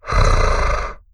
Monster_Hit7.wav